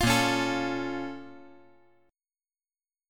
A#mM7 Chord
Listen to A#mM7 strummed